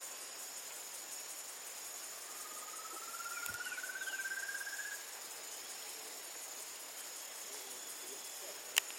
Rufous-tailed Antthrush (Chamaeza ruficauda)
Varios individuos
Life Stage: Adult
Location or protected area: Parque Provincial Moconá
Condition: Wild
Certainty: Recorded vocal